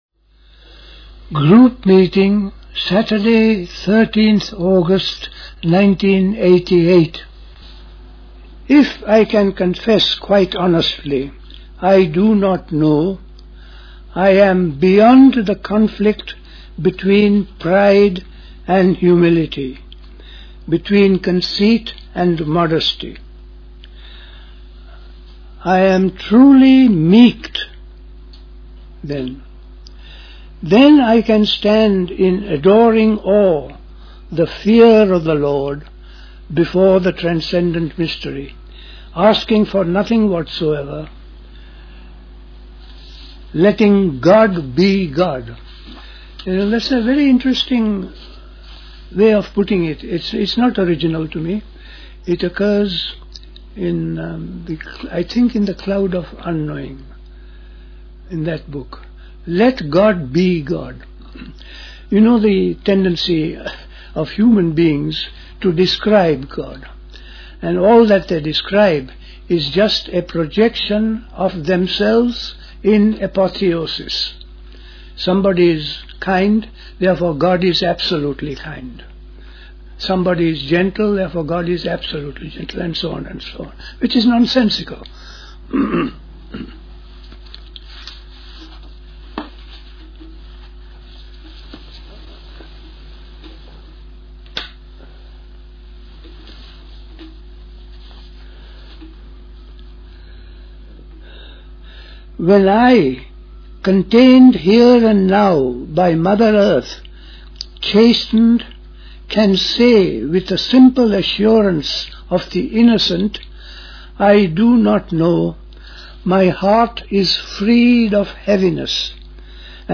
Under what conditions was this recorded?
at Dilkusha, Forest Hill, London